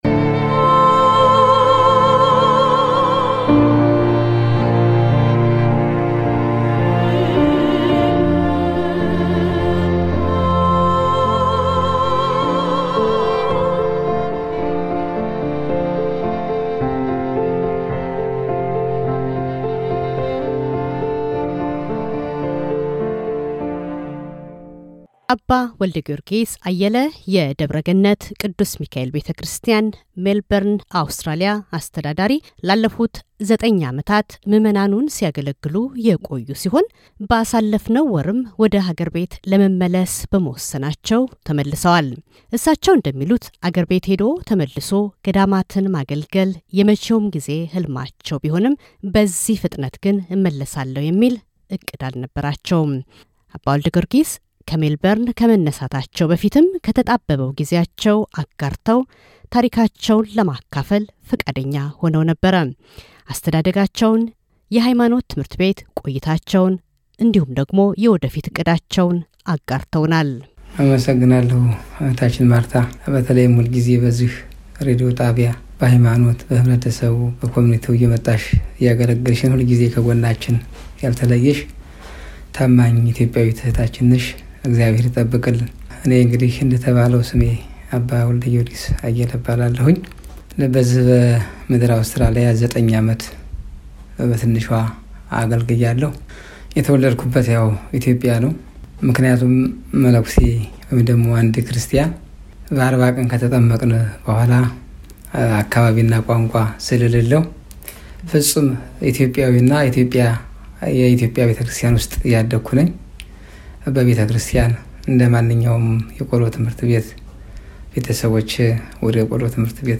ስለ ሕይወት ታሪካቸው፣ የመንፈሳዊ ሕይወት ጉዞአቸው ለአውስትራሊያ ምዕመናን ስላላቸው ከበሬታና እቅድ አጫውተውናል። ሕልፈተ ሕይወታቸውን ምክንያት በማድረግ ቃለ ምልልሳችን ደግመን አቅርበነዋል።